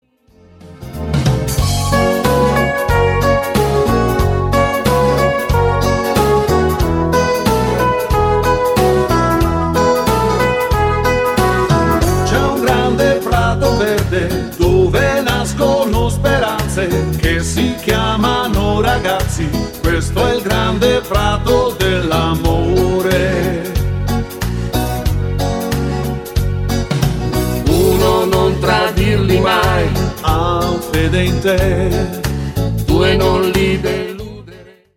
FOX - TROT  (6.02)